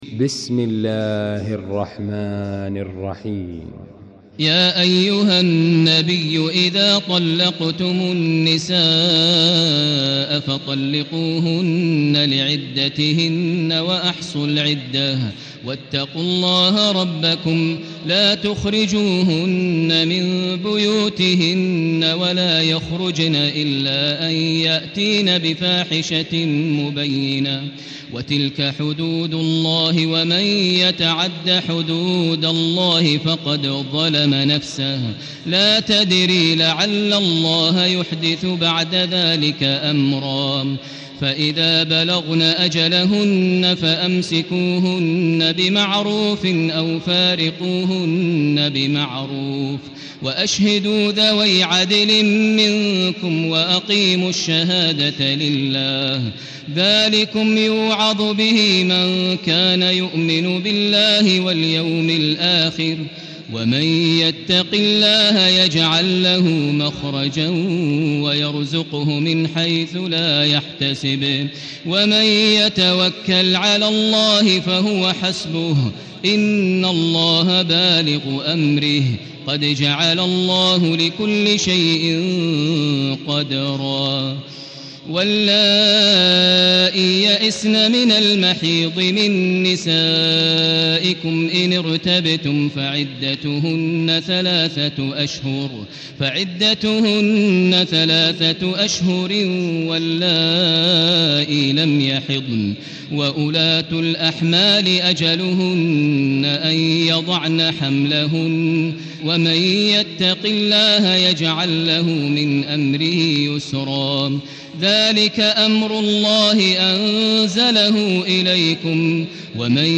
المكان: المسجد الحرام الشيخ: فضيلة الشيخ ماهر المعيقلي فضيلة الشيخ ماهر المعيقلي الطلاق